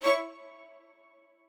admin-leaf-alice-in-misanthrope/strings34_2_012.ogg at main